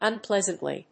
/ʌˈnplɛzʌntli(米国英語), ʌˈnplezʌntli:(英国英語)/